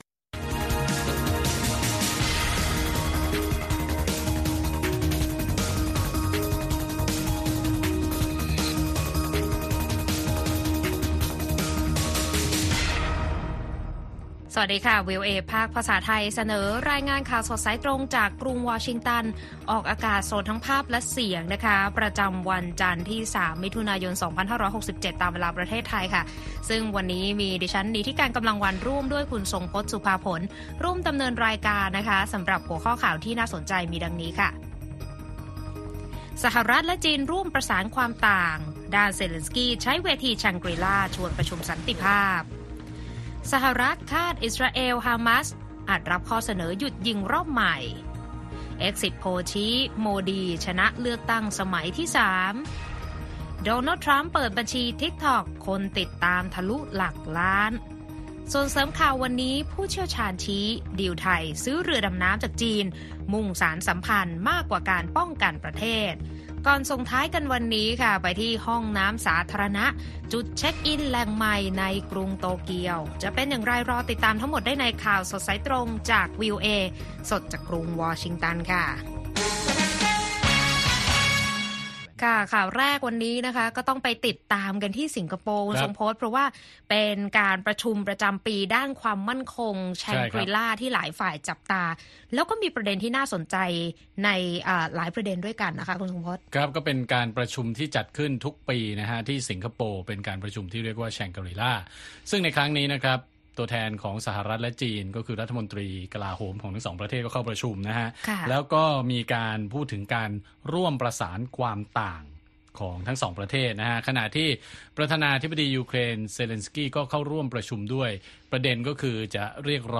ข่าวสดสายตรงจากวีโอเอไทย วันจันทร์ ที่ 3 มิถุนายน 2024